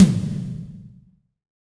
HEXTOMHI1.wav